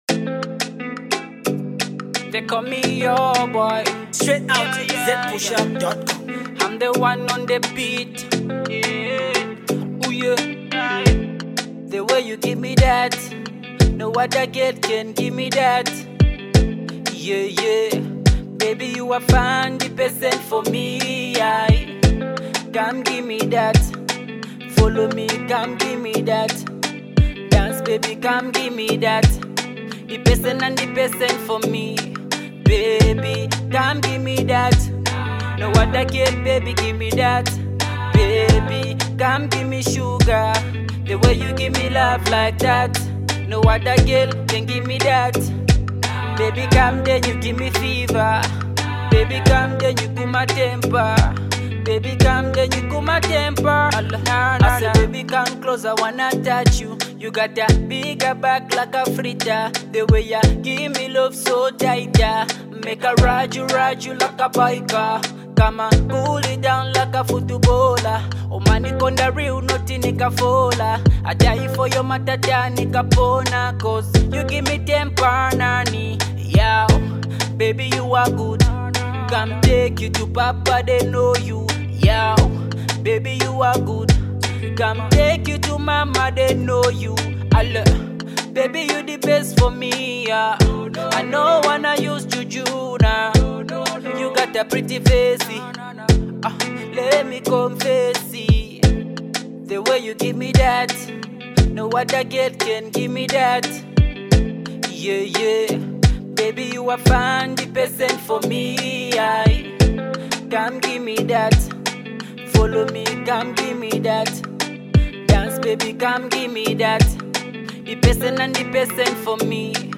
Zambia’s top uprising singer